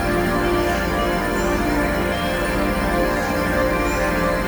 Magical_EnergyLoop03.wav